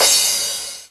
cch_perc_crash_high_tail_purple.wav